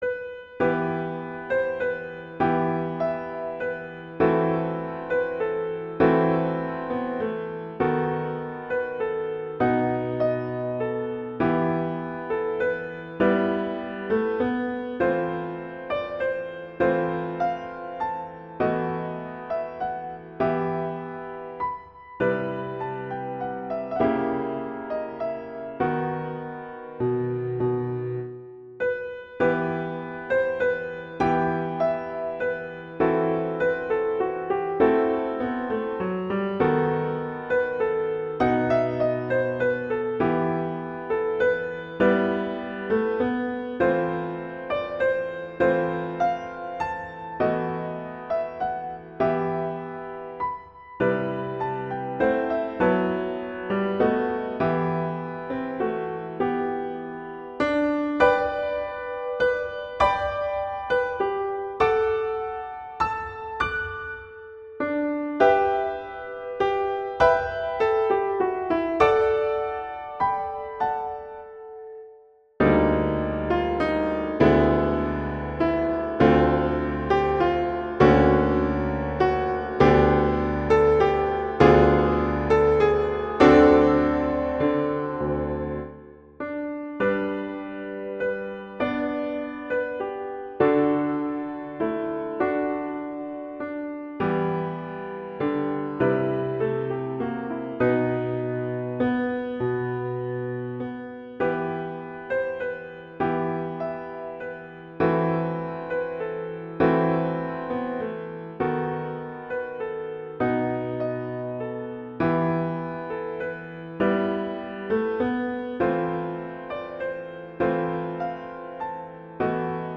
classical
♩. = 33 BPM (real metronome 40 BPM)
D2-F#6